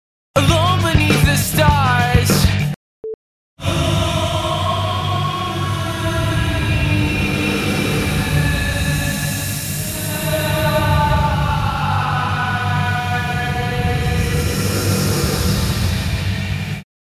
That “endless” effect sounds like “time stretch”.